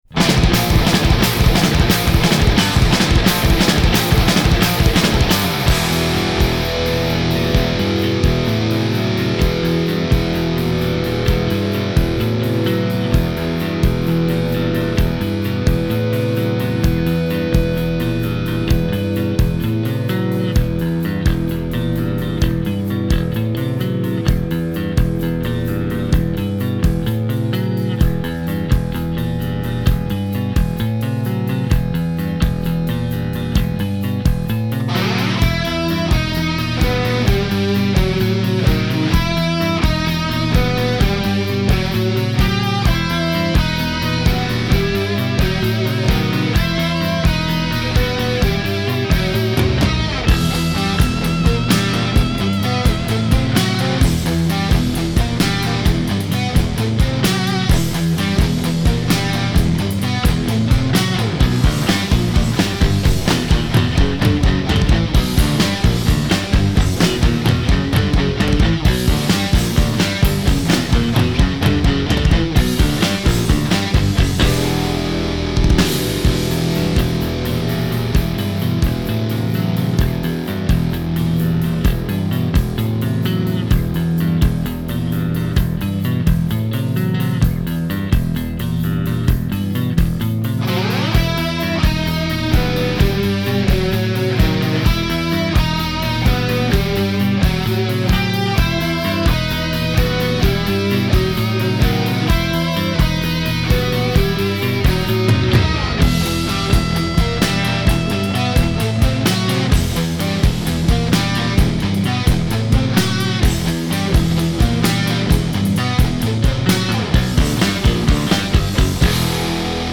Genre : Metal